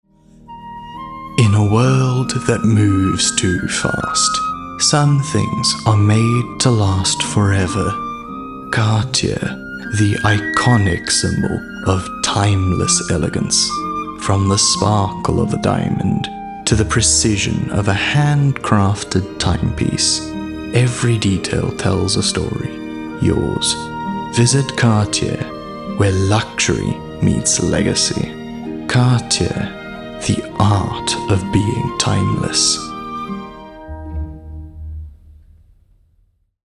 animation, articulate, authoritative, character, Deep
Luxury- Cartier